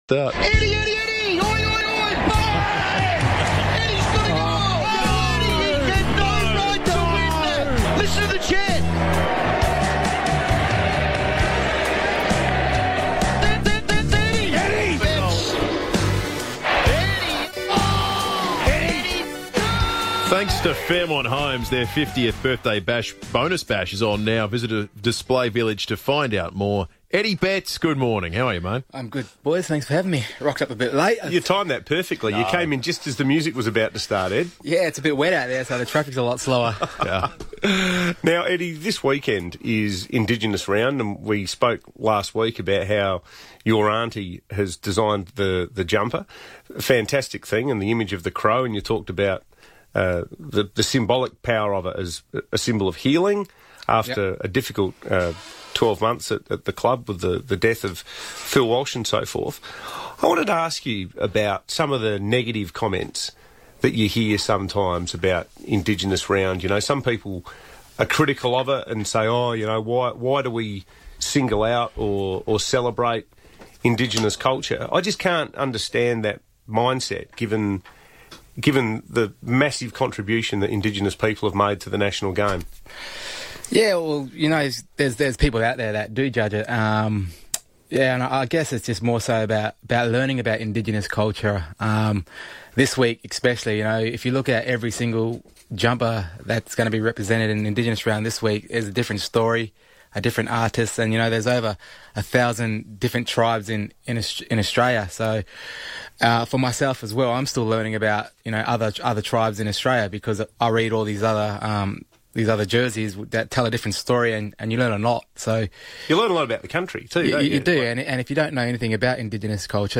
Eddie Betts joined the FIVEaa Breakfast team ahead of Adelaide's Sir Doug Nicholls Indigenous Round clash with Greater Western Sydney.